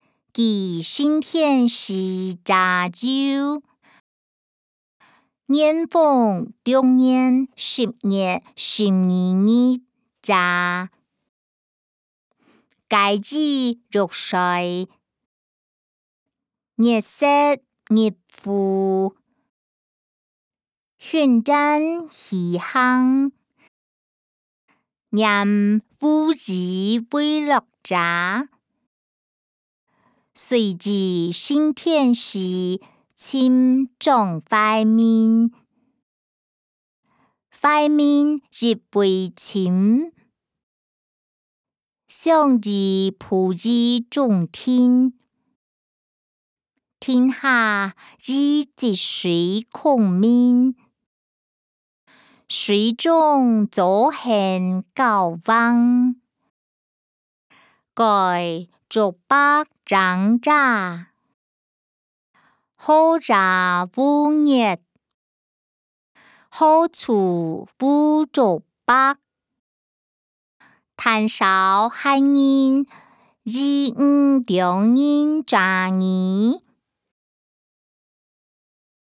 歷代散文-記承天寺夜遊音檔(海陸腔)